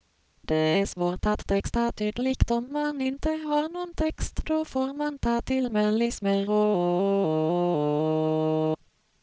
Burcas is a modest attempt at concatenated singing synthesis for Swedish. The system employs the MBROLA speech generator and a prerecorded diphone data base.
extended vowels and melismas do not.
skala_fast.wav